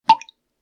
drip.ogg